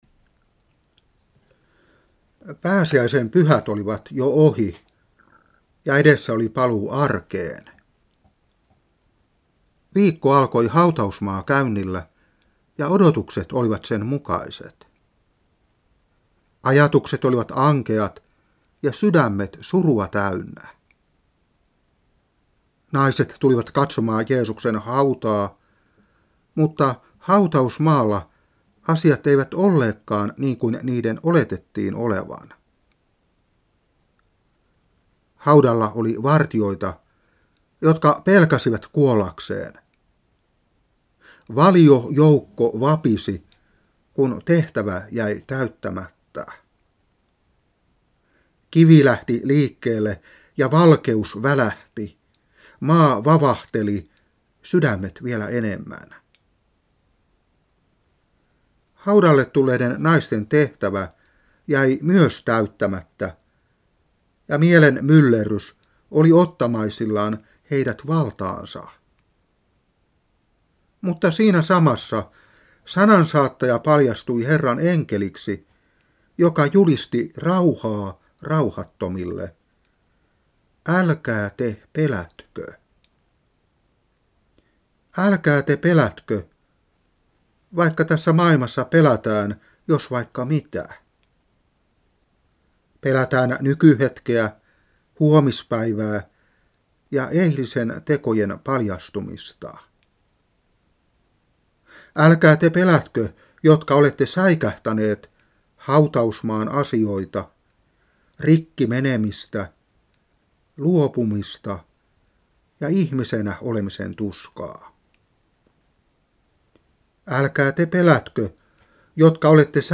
Saarna 1998-4.